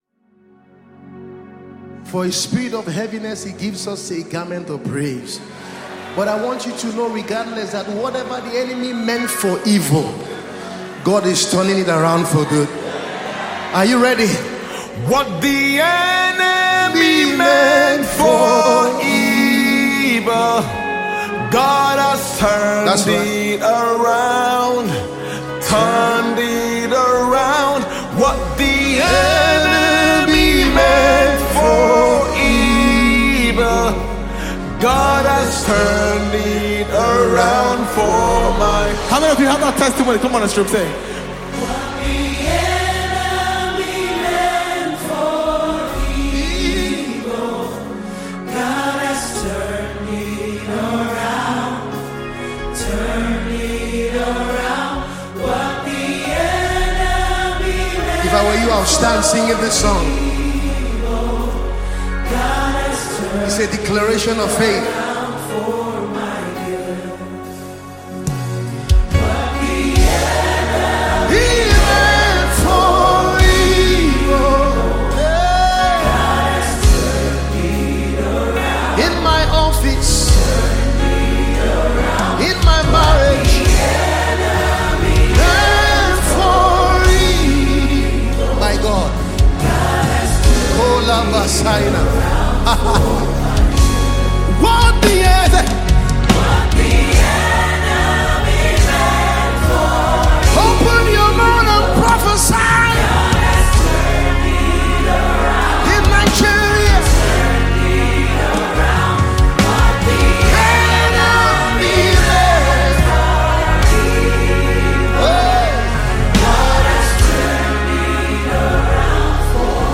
known for his soulful trumpet prowess